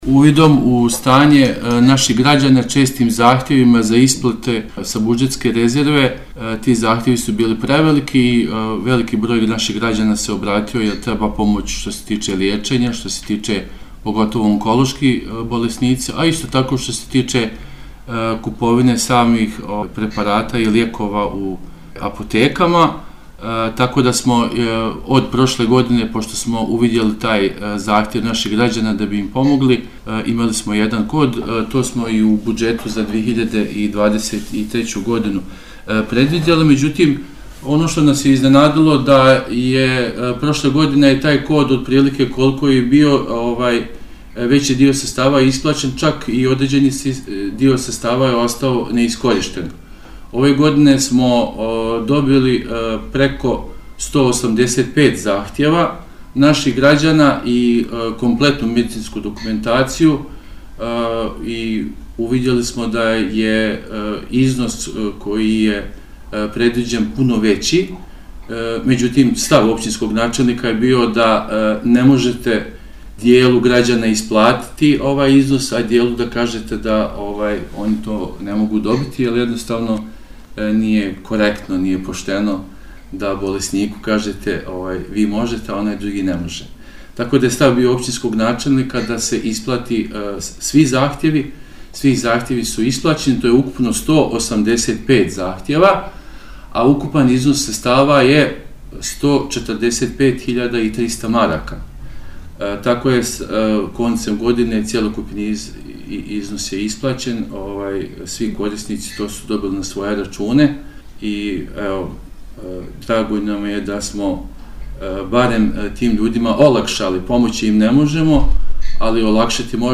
(poslušajte izjavu)